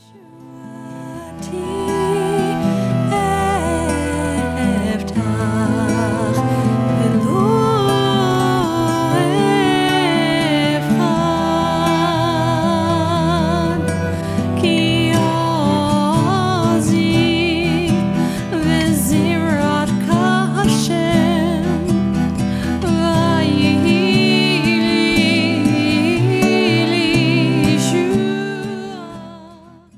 Recorded with top Israeli session players.